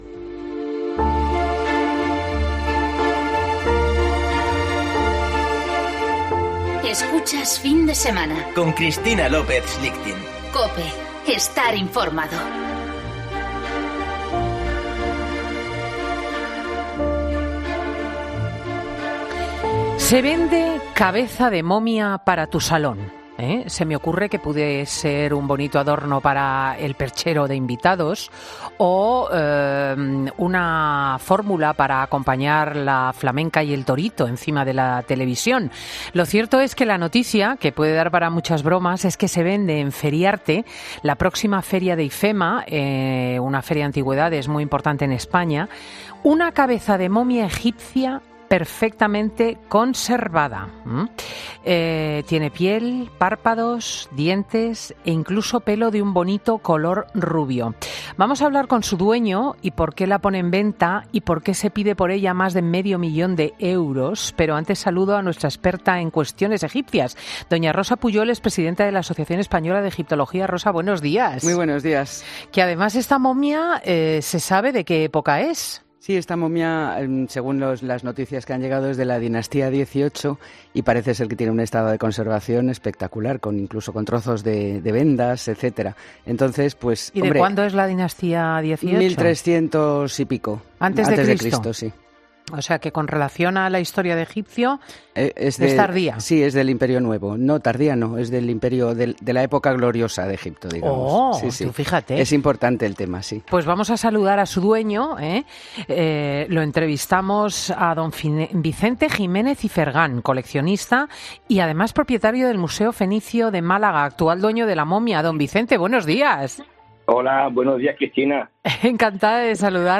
Redacción digital Madrid - Publicado el 26 oct 2019, 14:03 - Actualizado 16 mar 2023, 11:44 2 min lectura Descargar Facebook Twitter Whatsapp Telegram Enviar por email Copiar enlace "Fin de Semana" es un programa presentado por Cristina López Schlichting , prestigiosa comunicadora de radio y articulista en prensa, es un magazine que se emite en COPE , los sábados y domingos, de 10.00 a 14.00 horas.